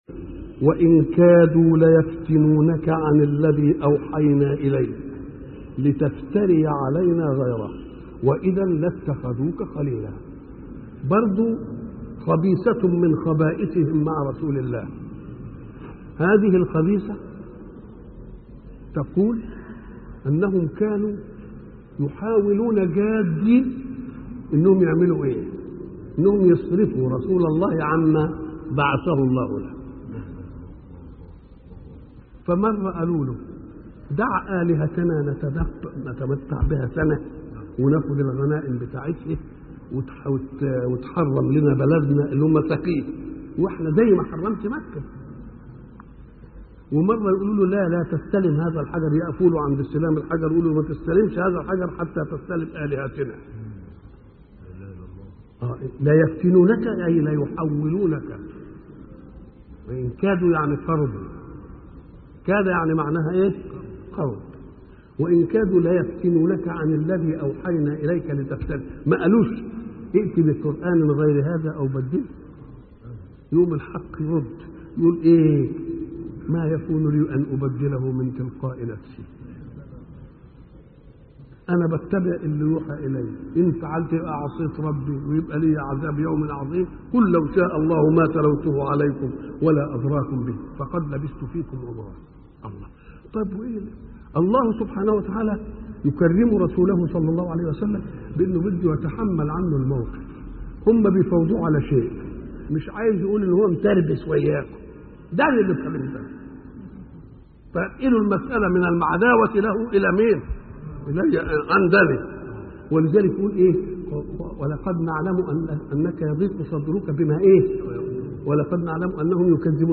شبكة المعرفة الإسلامية | الدروس | عصمة النبي من الفتنة |محمد متولي الشعراوي